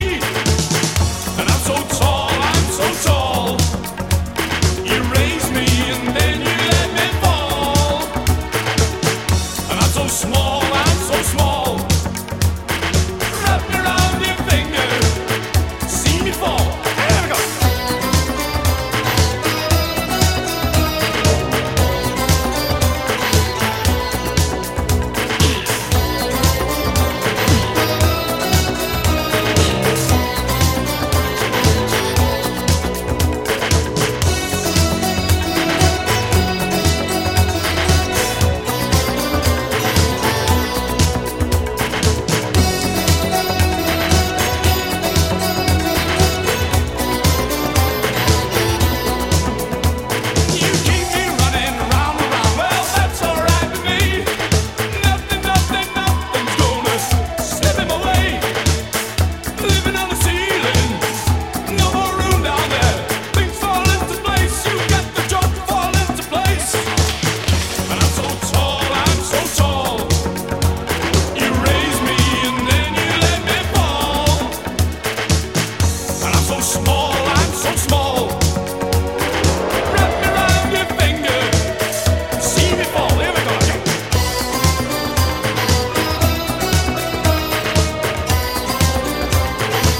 A synth-pop classic